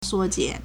缩减 (縮減) suōjiǎn
suo1jian3.mp3